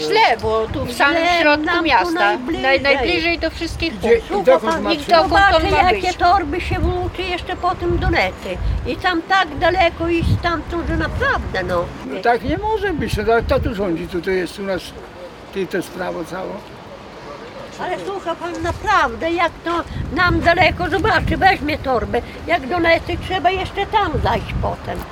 Mieszkańcy-augustowa-o-zmianie-miejsca-PKS.mp3